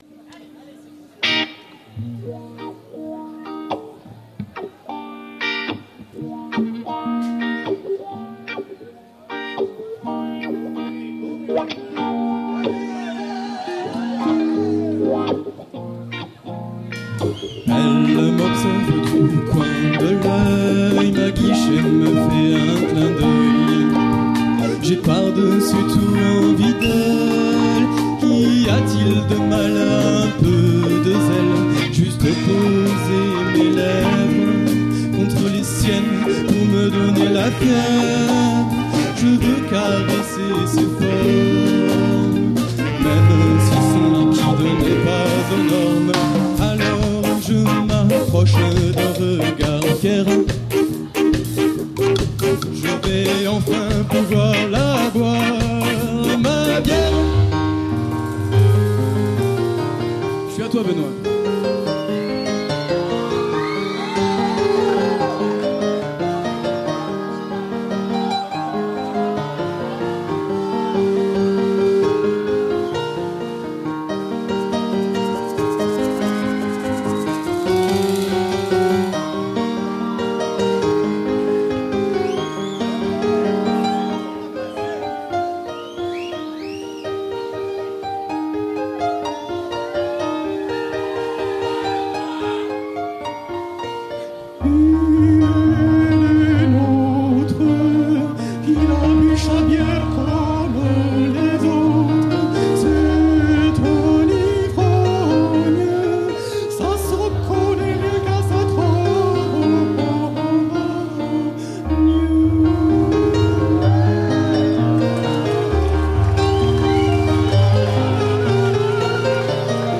enregistrement en live au Havana en Février 2004